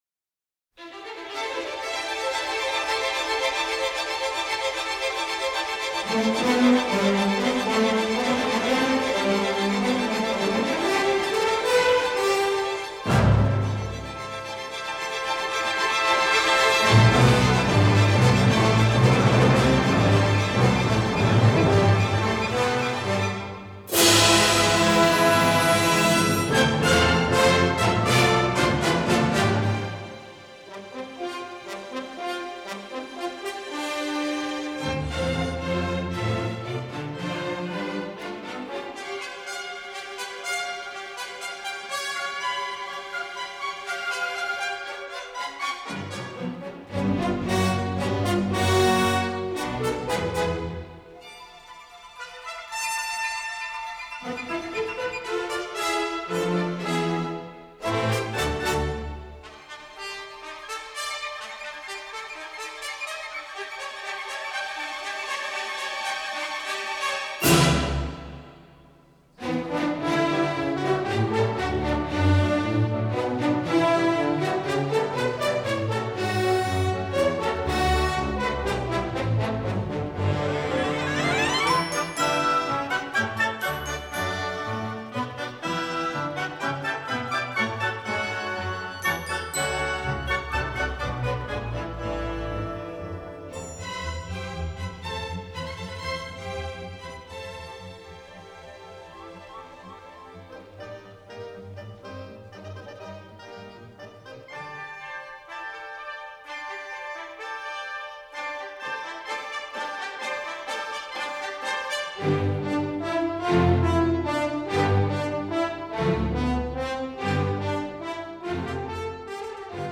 slow trek through the “big tune”